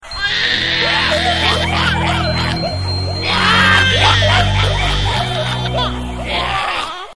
new-crying-baby_21255.mp3